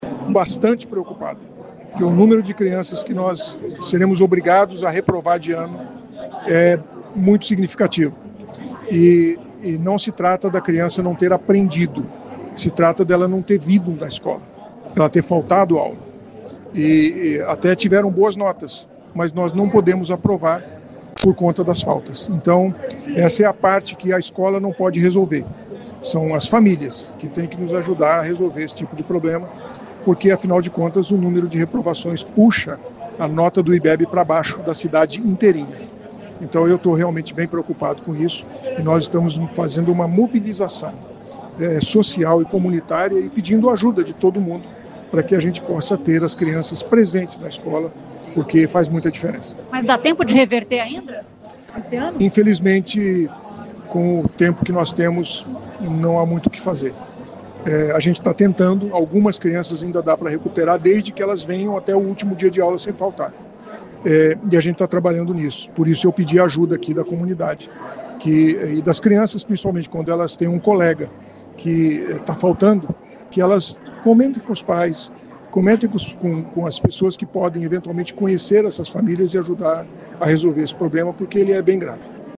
Em cerimônia de entrega de um Cmei, o prefeito Sílvio Barros pediu aos pais que alertem outros pais sobre a frequência escolar. O número de alunos da rede municipal de ensino que podem reprovar este ano por falta é preocupante.
O prefeito diz que está preocupado: